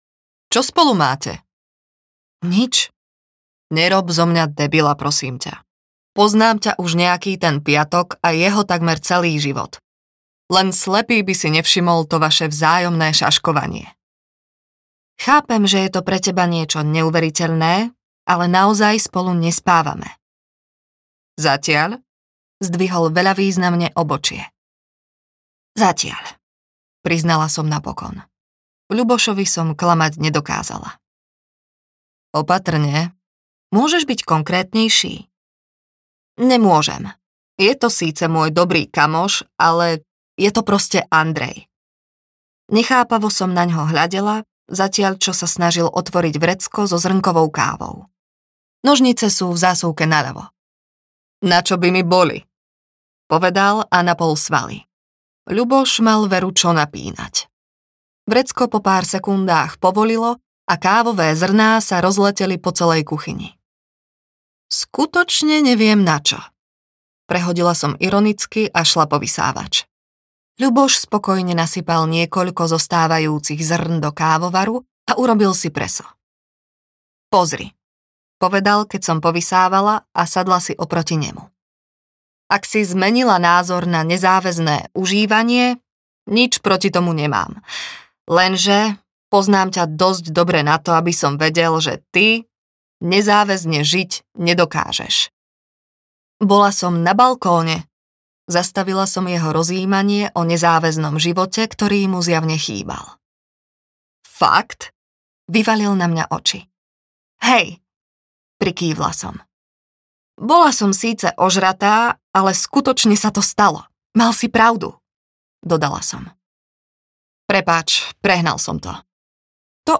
Audio knihaNeviditeľná
Ukázka z knihy